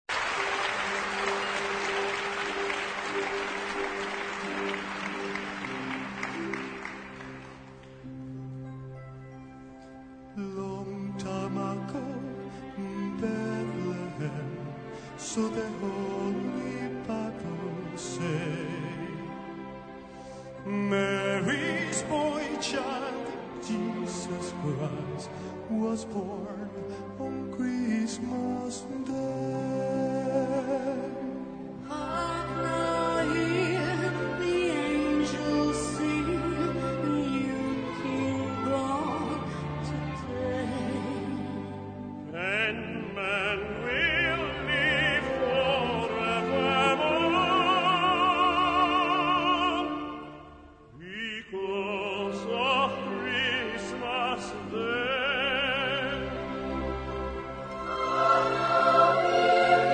key: C-major